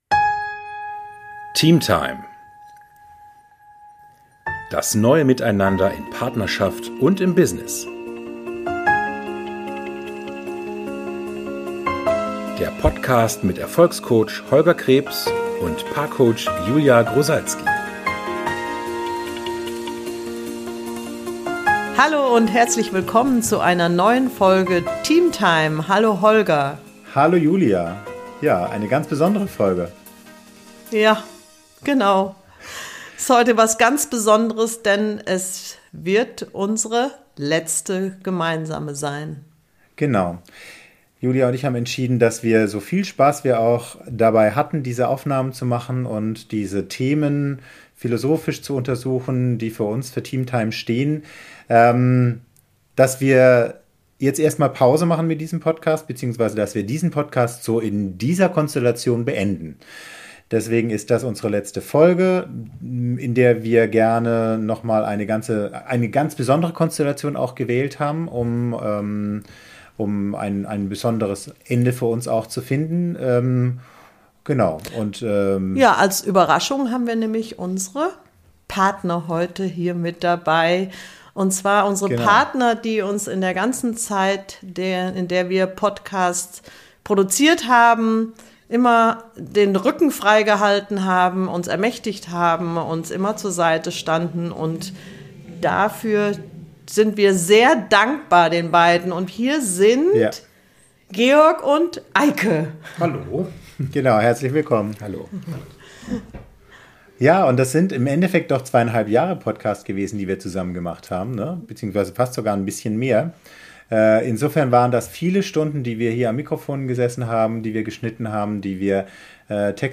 Zum guten Schluss sprechen wir in dieser Folge zu viert mit unseren Partnern darüber, was TeamTime im Business aber unbedingt auch im Privaten so toll macht.